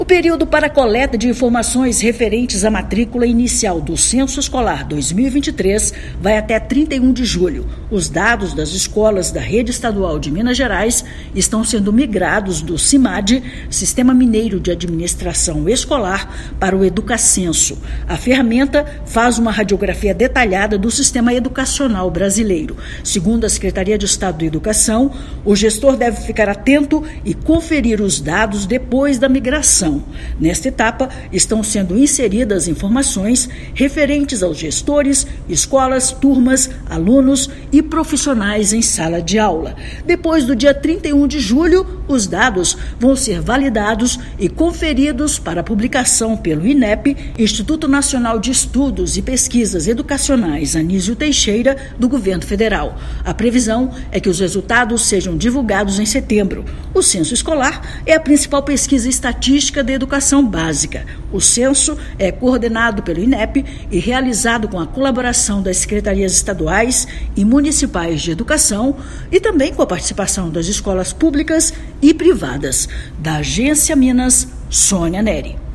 Estatísticas de matrículas servem de base para o repasse de recursos do Governo Federal, além do planejamento e divulgação das avaliações realizadas pelo Inep. Ouça matéria de rádio.